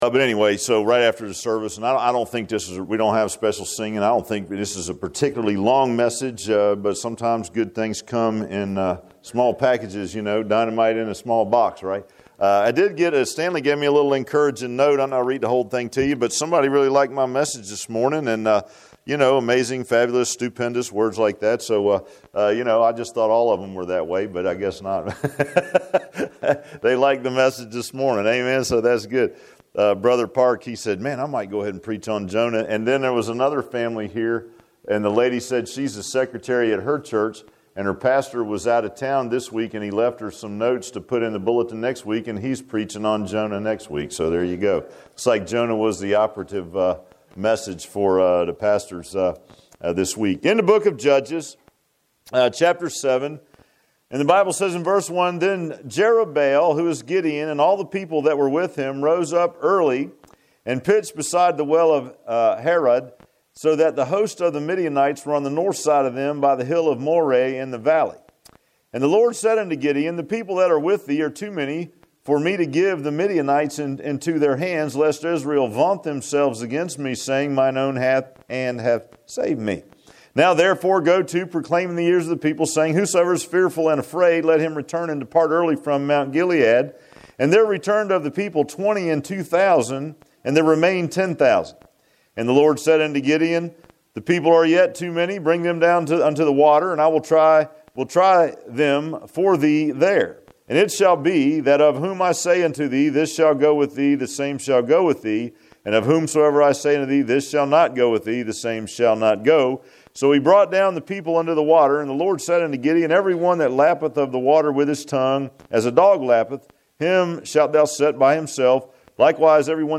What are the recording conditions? Judges 7:1-7 Service Type: Sunday PM Bible Text